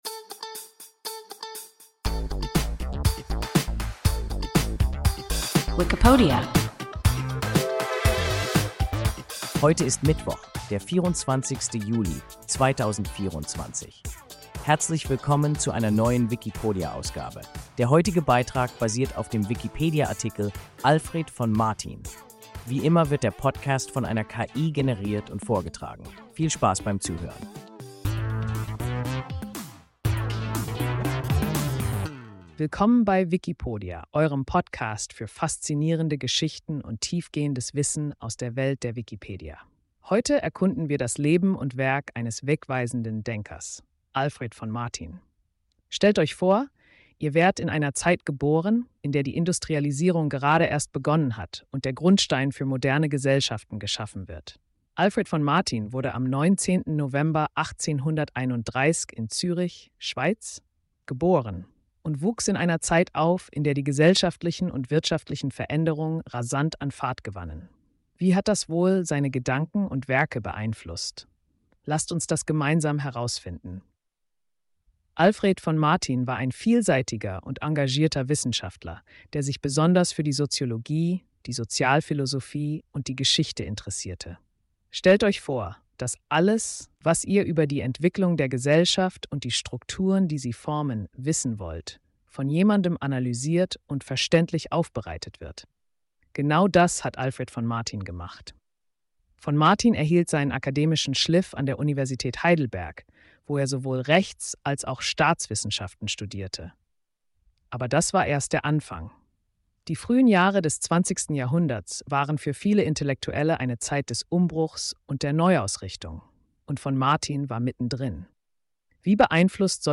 Alfred von Martin – WIKIPODIA – ein KI Podcast